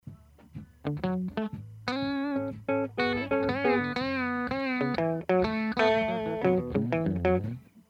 guitares.mp3